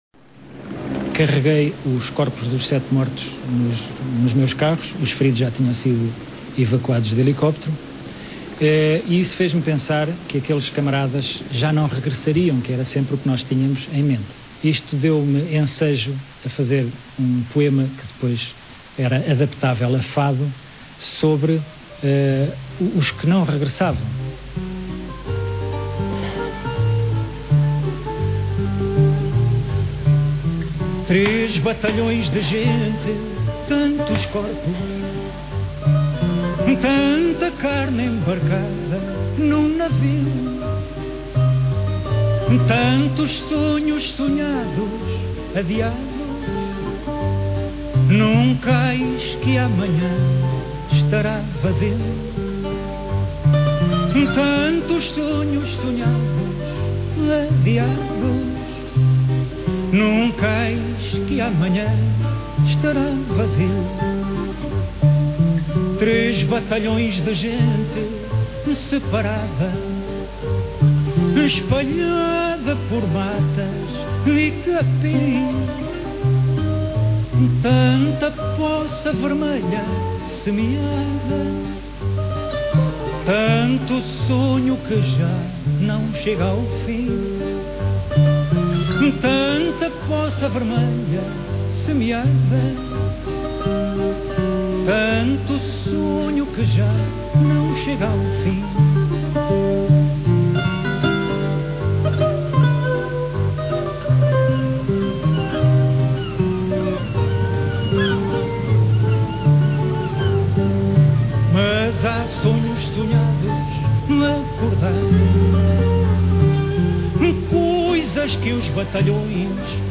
Guitarra
Viola